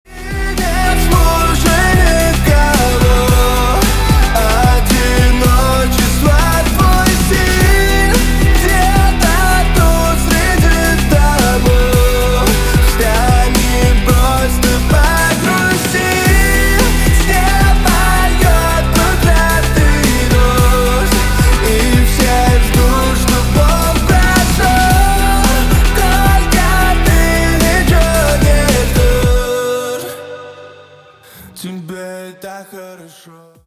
на русском грустные